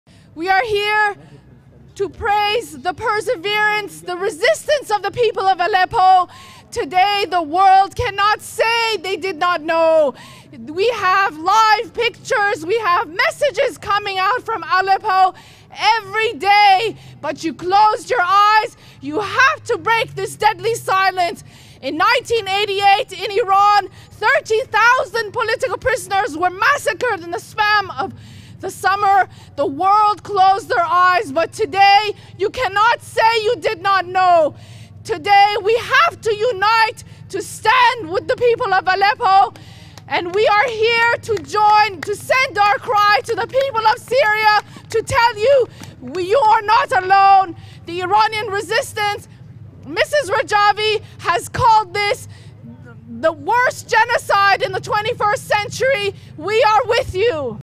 representative of the Women’s Committee of the National Council of Resistance of Iran speaks in Paris demonstration in solidarity with the people of Syria, against genocide in Aleppo